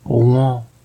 Rouen (UK: /ˈrɒ̃, ˈrɒn/, US: /rˈɒ̃, rˈɒn/;[3][4] French: [ʁwɑ̃]